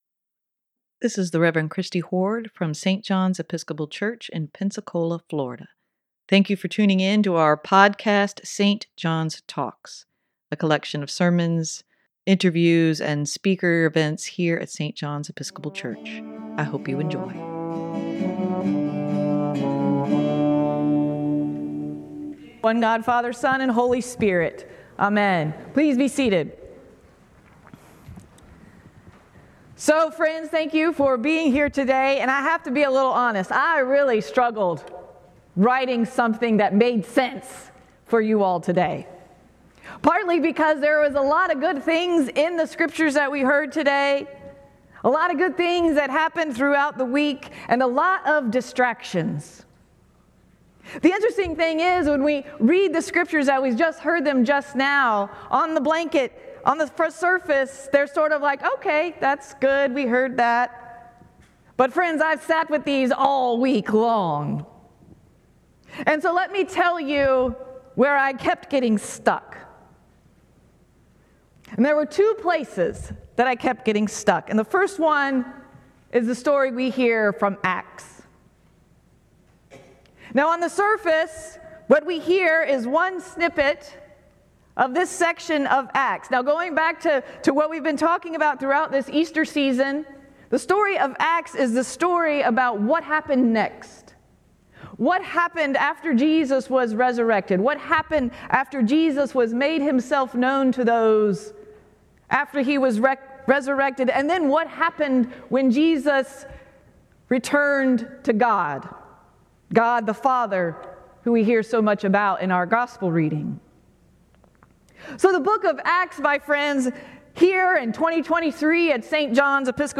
Sermon for May 7, 2023: Deciding day after day to follow Jesus - St. John's Episcopal Church
sermon-5-7-23.mp3